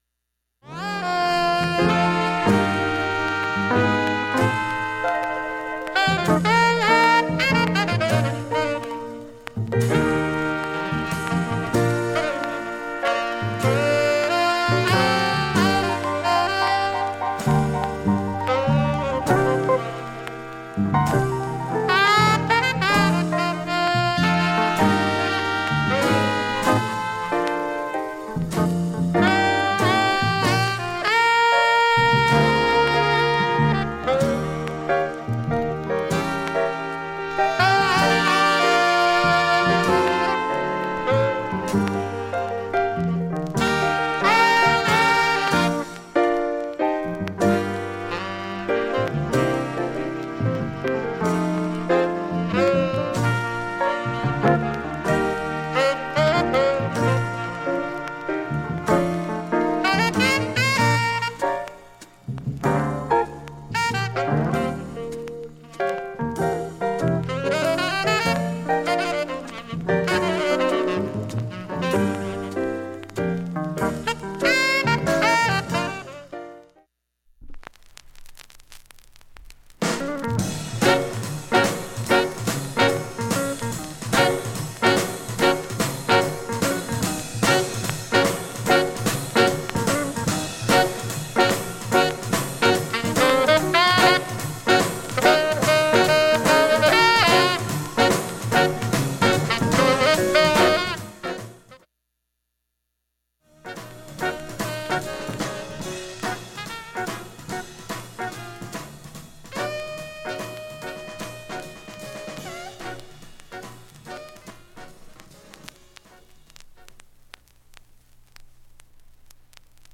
ですがバックチリなどはほとんど聴こえません
各面進むにつれてチリは少なくなります。
演奏中はおろか無音部も問題はありません。
1,A-2中盤に３ミリスレで７７秒の間プツ出ます
2,(1m18s〜)曲間無音部などダイジェスト３分ほど
８回までのかすかなプツが５箇所
５回までのかすかなプツが７箇所
３回までのかすかなプツが４箇所
◆ＵＳＡ盤 Reissue Stereo